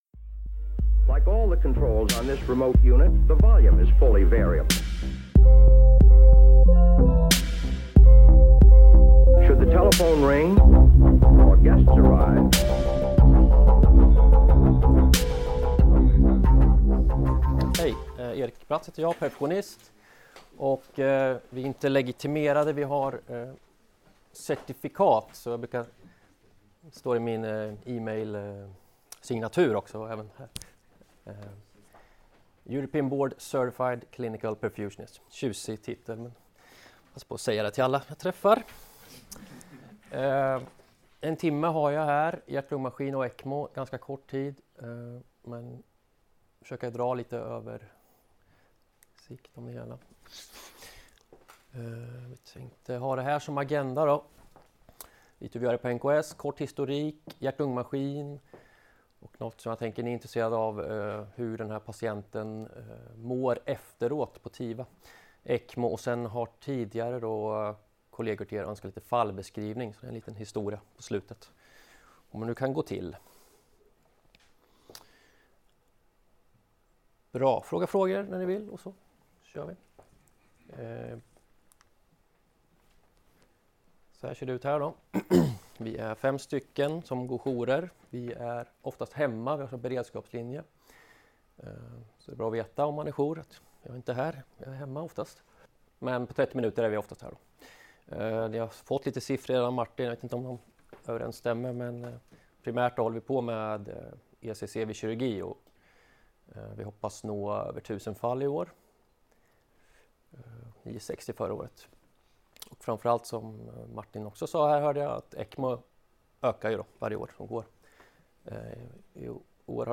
Föreläsningen hölls på ST-fredagen med tema thoraxanestesi och thoraxintensivvård den 4 oktober 2019, på Karolinska i Solna.